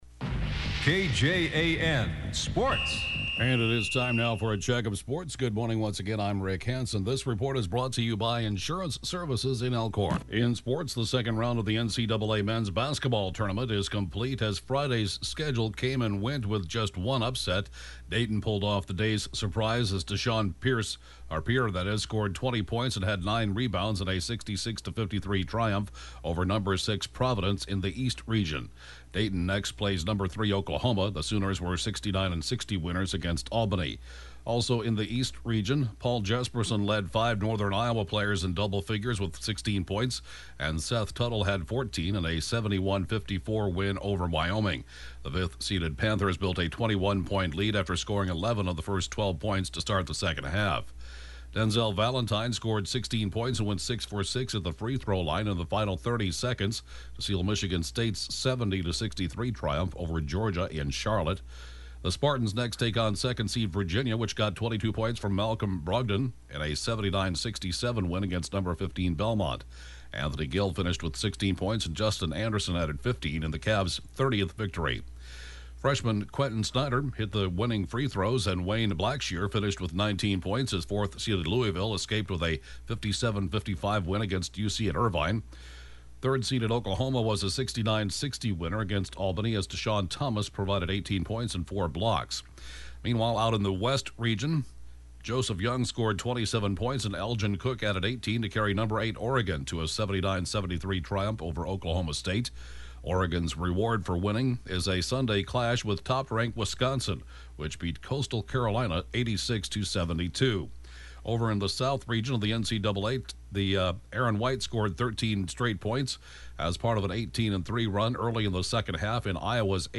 (Podcast) KJAN Sports report, 11/4/2015